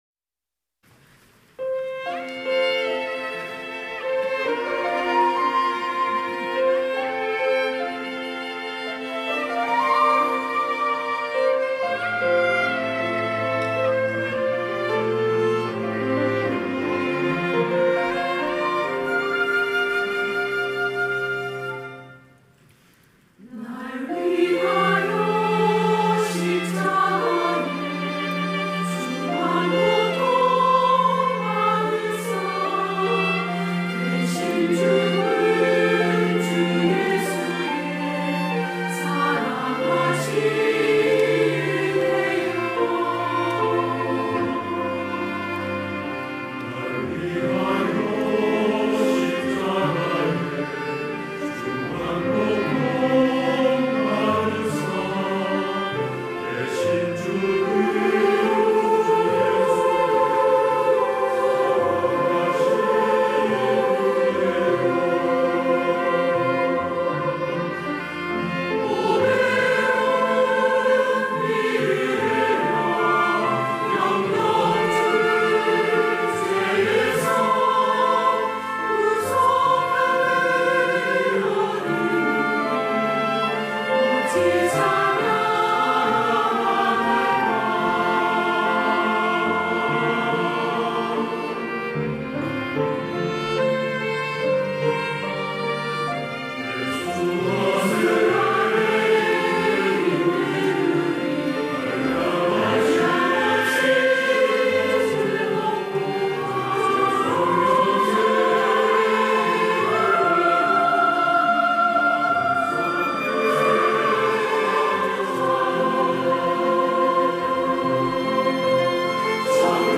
할렐루야(주일2부) - 날 위하여 십자가에
찬양대